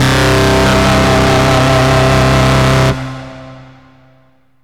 SYNTH LEADS-1 0012.wav